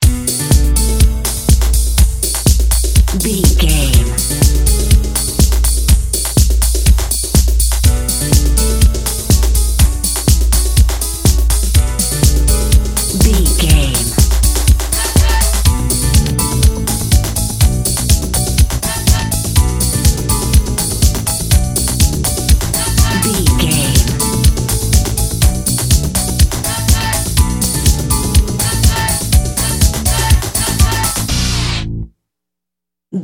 Dorian
Fast
drum machine
synthesiser
electric piano
Eurodance